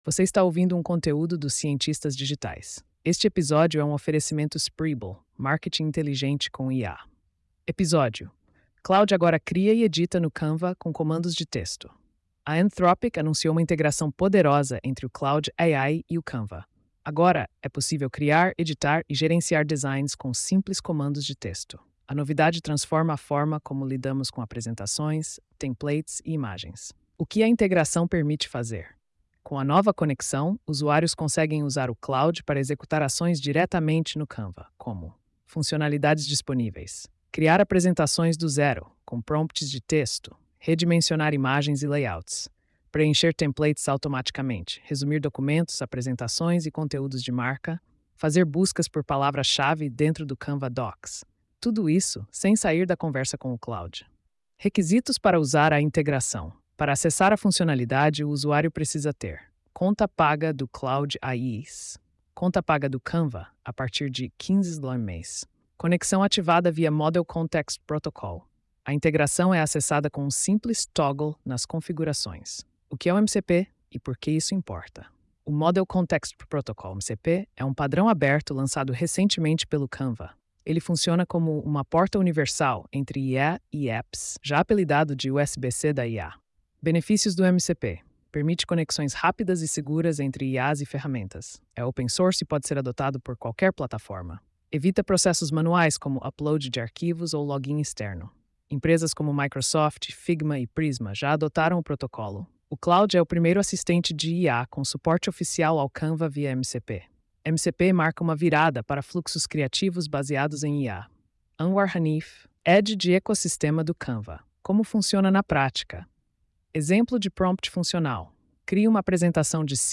post-3370-tts.mp3